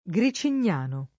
Gricignano [ g ri © in’n’ # no ]